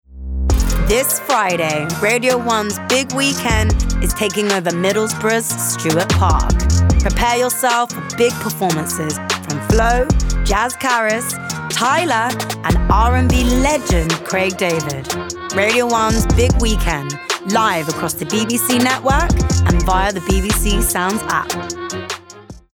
London
Cool Radio Presenter